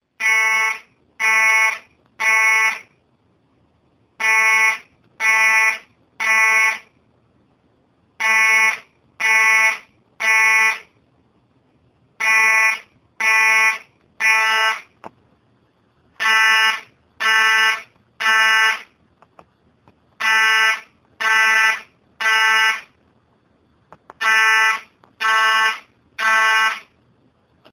firealarm.ogg